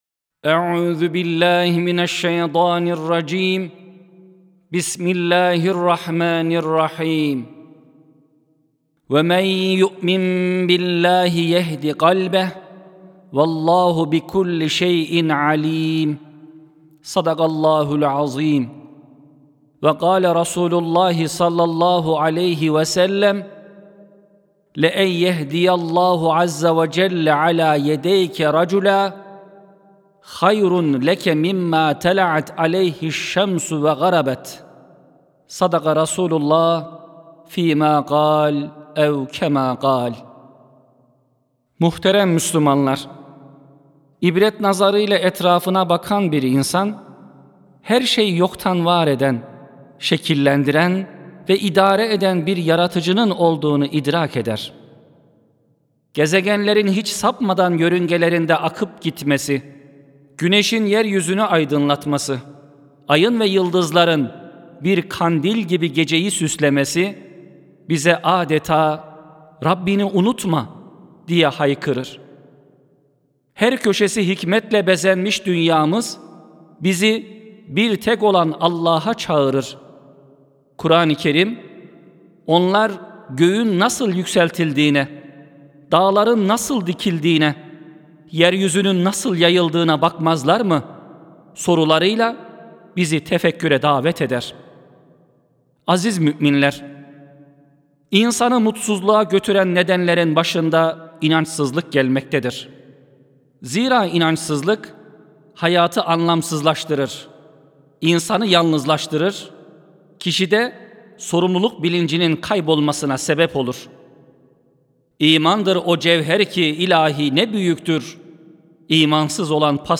2 Ocak 2026 Tarihli Cuma Hutbesi
Sesli Hutbe (Her Şey Allah'ı Anlatır).mp3